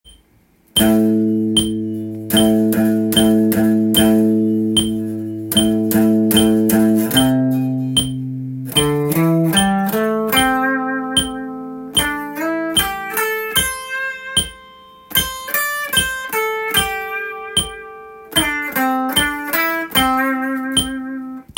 スケールでリズム練習tab
③のリズムは２分音符で伸ばした後に８分音符が４つきますので